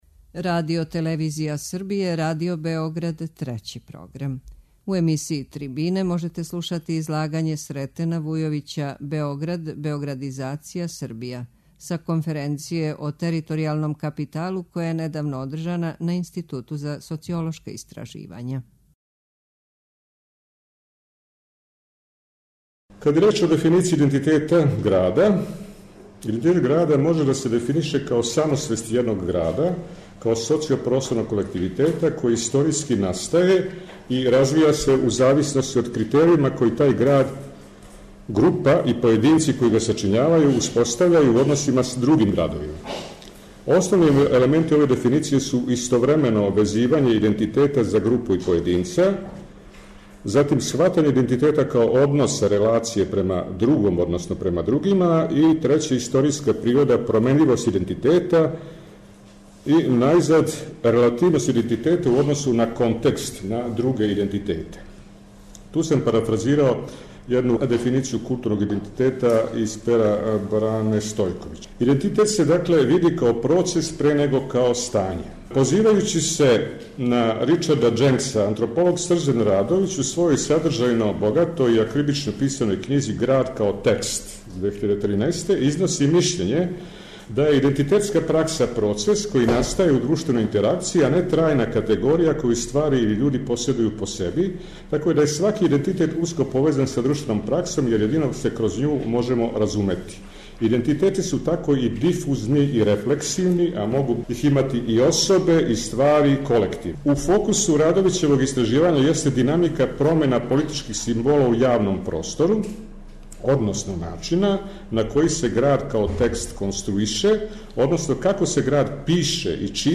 Трибине
преузми : 6.91 MB Трибине и Научни скупови Autor: Редакција Преносимо излагања са научних конференција и трибина.